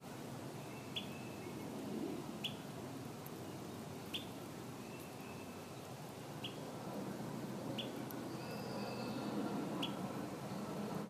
Alder Flycatchers
An ALFL is heard pip-calling in the following recording:
All of the recording in today’s post were made using iPhones. The .mp4 files were converted to .wav files and their volumes boosted using the freeware Audacity on a Windows 7 laptop.